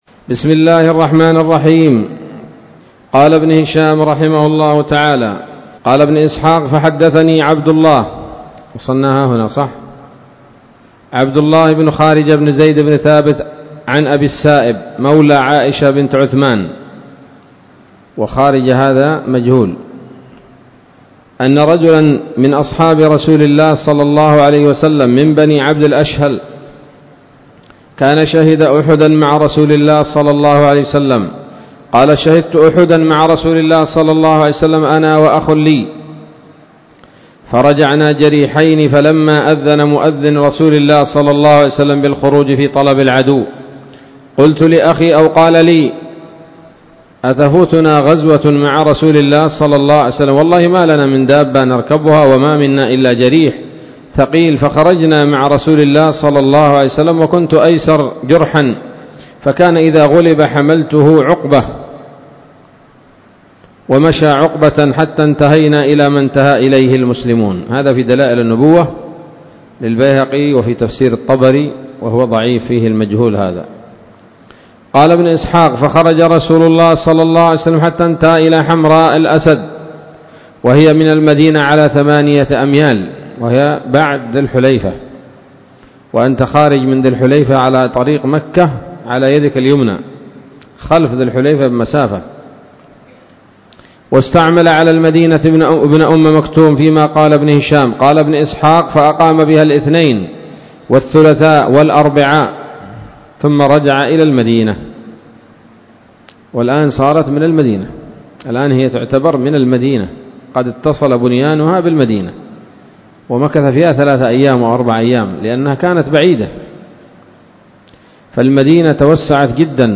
الدرس الثامن والستون بعد المائة من التعليق على كتاب السيرة النبوية لابن هشام